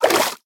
assets / minecraft / sounds / liquid / swim4.ogg
swim4.ogg